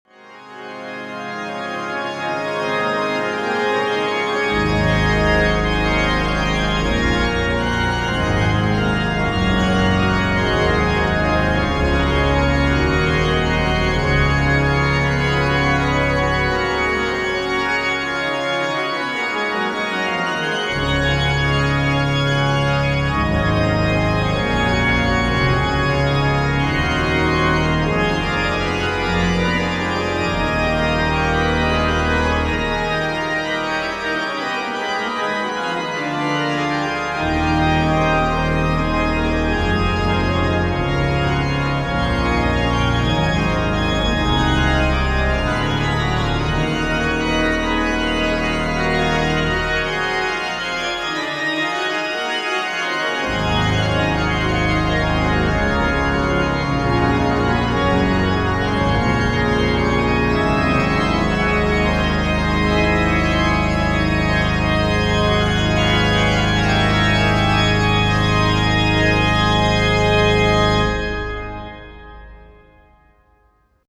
What better instrument to play it on other than the splendid new Bach organ in Thomaskiche Leipzig.
Registrations are clear, incisive and perfectly balanced, full of colour and show off the wonderful voicing of the instrument.